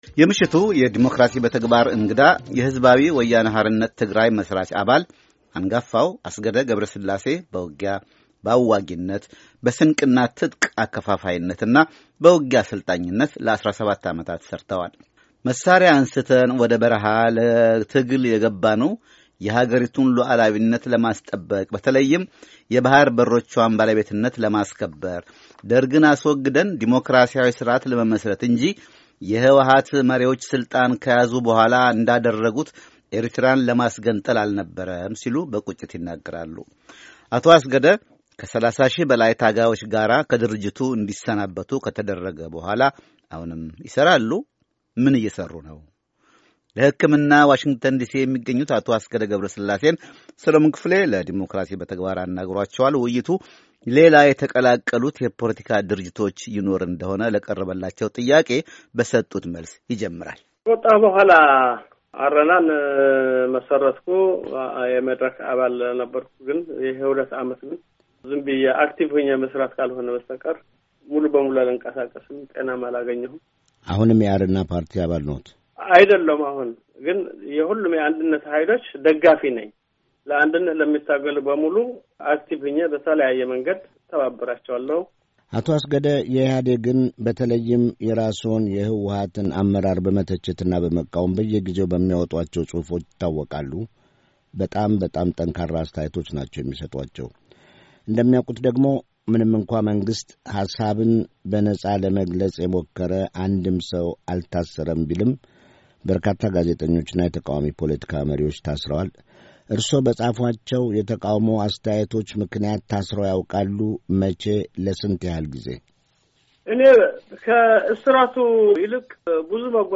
ቃለ ምልልስ